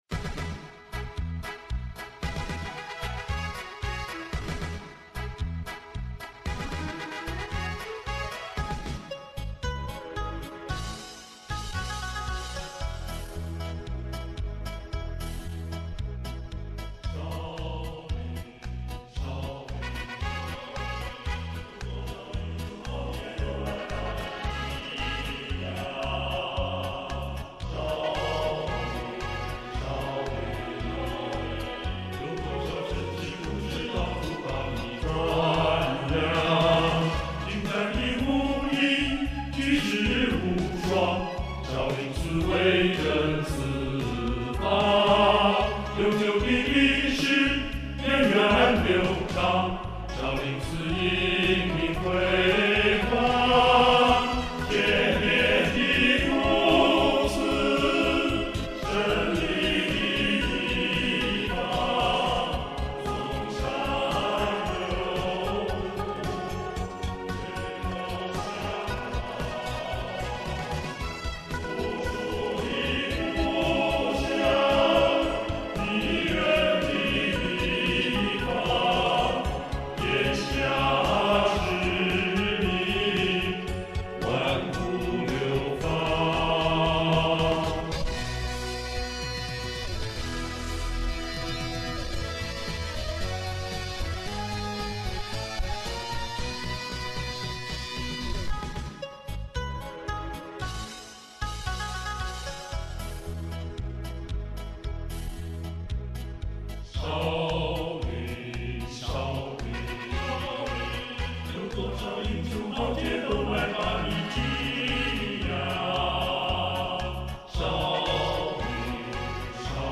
男声网络数码大合唱